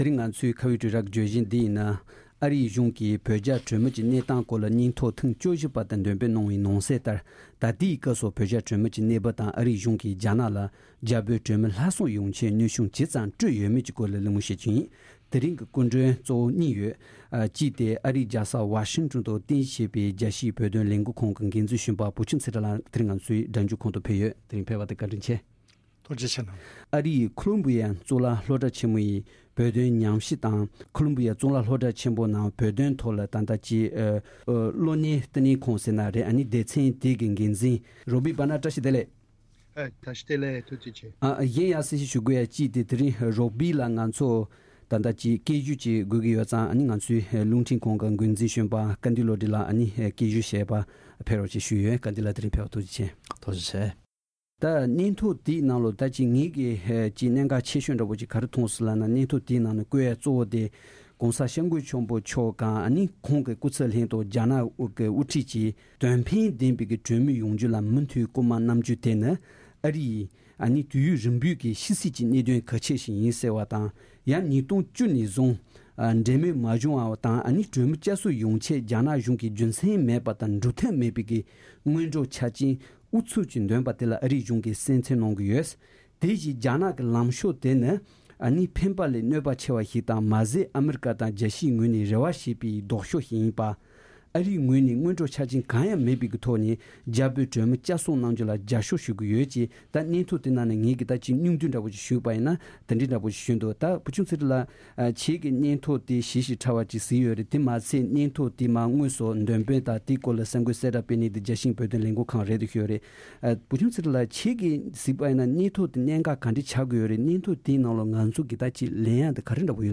ཁ་བའི་གྲོས་ར་བརྗོད་གཞི་ནང་ཨ་རིའི་གཞུང་གིས་བོད་རྒྱ་གྲོས་མོལ་གྱི་གནས་སྟངས་སྐོར་སྙན་ཐོ་ཐེངས་༡༤་པ་དེ་འདོན་སྤེལ་གནང་བའི་ནང་གསལ་ལྟར།་ད་ལྟའི་སྐབས་སུ་བོད་རྒྱ་གྲོས་མོལ་གྱི་གནས་བབས་དང་ཨ་རིའི་གཞུང་གིས་རྒྱ་ནག་ལ་རྒྱ་བོད་གྲོས་མོལ་སླར་གསོ་ཆེད་གནོན་ཤུགས་ཇི་ཙམ་སྤྲོད་ཡོད་མེད་གྱི་སྐོར་གླེང་མོལ་གནང་བ་གསན་རོགས་གནང་།